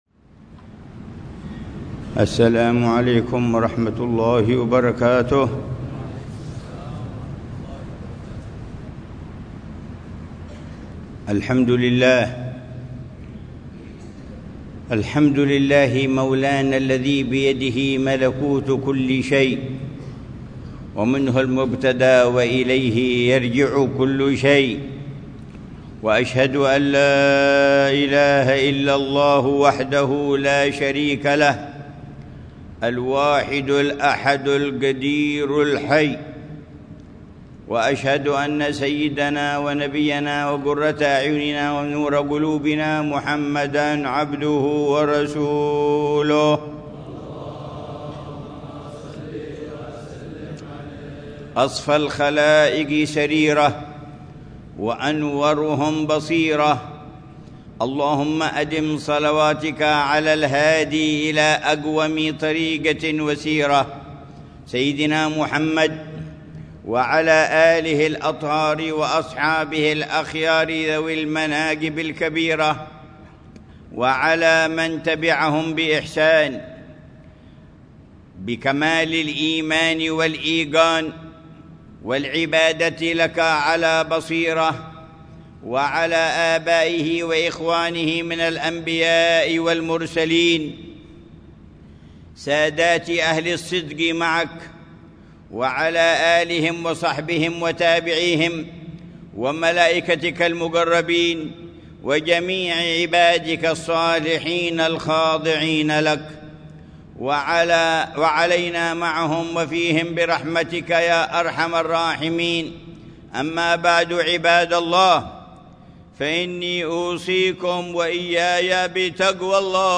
خطبة الجمعة للعلامة الحبيب عمر بن محمد بن حفيظ في جامع الإيمان، بحارة الإيمان، عيديد، مدينة تريم، 11 ذو القعدة 1446هـ بعنوان: